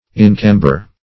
Search Result for " inchamber" : The Collaborative International Dictionary of English v.0.48: Inchamber \In*cham"ber\, v. t. [imp.